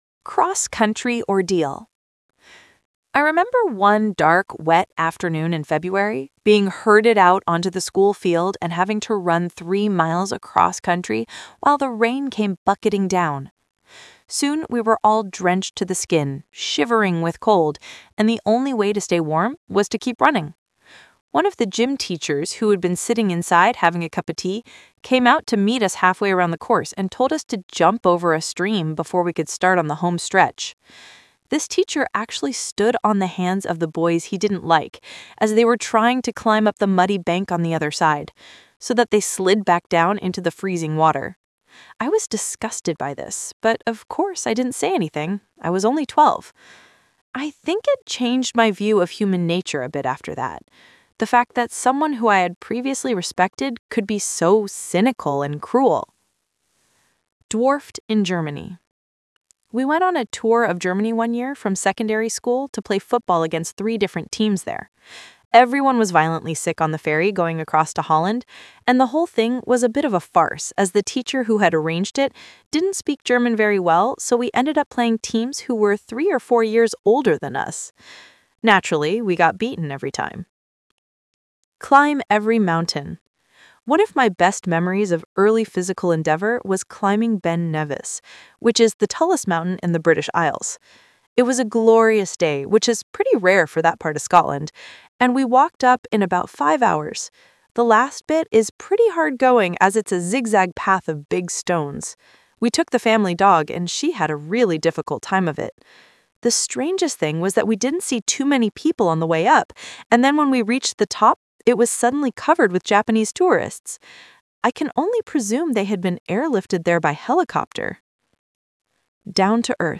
Talk/Lecture 2: You will hear people talking about their sport experience.